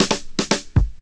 11DR.BREAK.wav